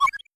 Alert6.wav